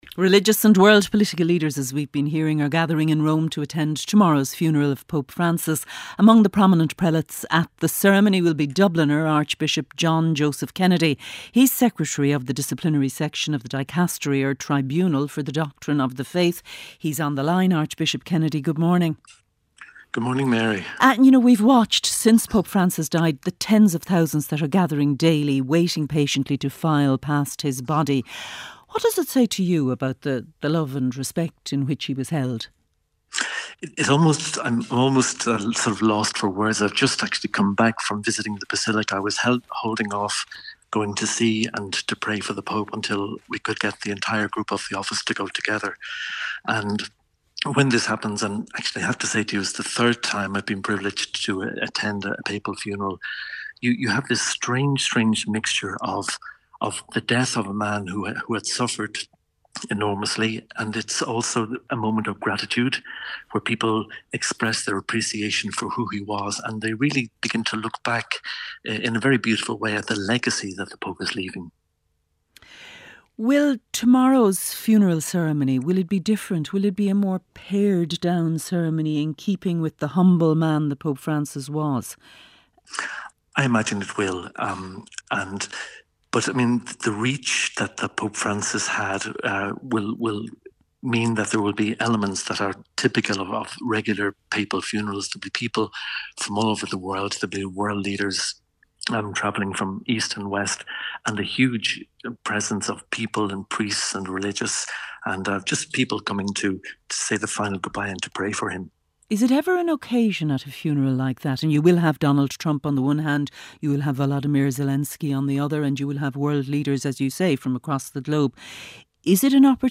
RTÉ's flagship news and current affairs radio programme and the most listened-to show in Ireland, featuring the latest news and analysis with Gavin Jennings, Audrey Carville, Áine Lawlor and Mary Wilson.